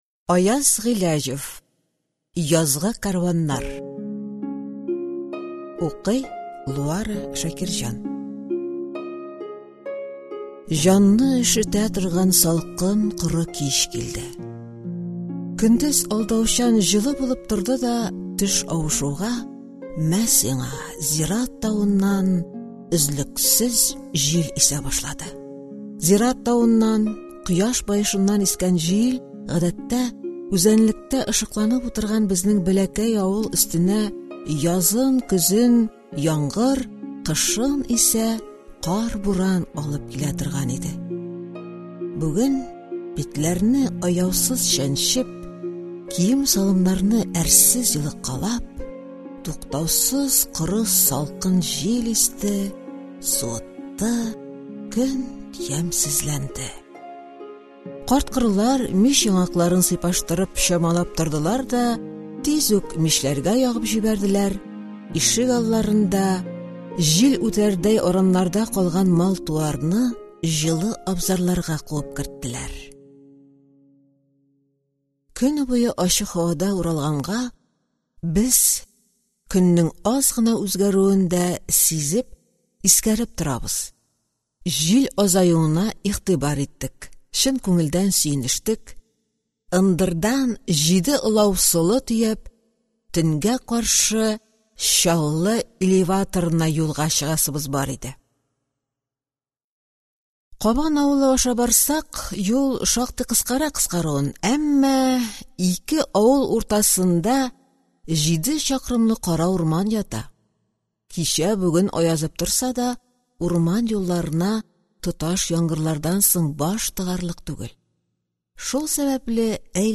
Аудиокнига Язгы кәрваннар | Библиотека аудиокниг